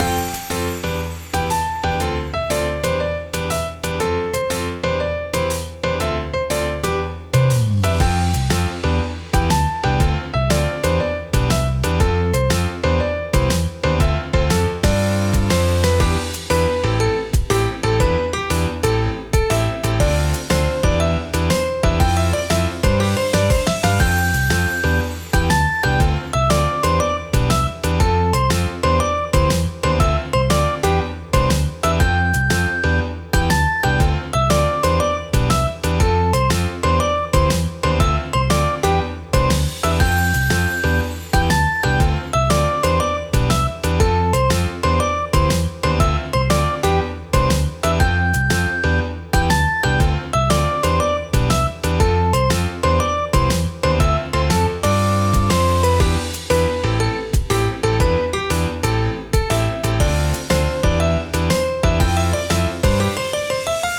【ループ版あり】 明るい雰囲気のほのぼのとした日常系BGMです
のんびり/ゆったり/コミカル/トーク
▼ループ版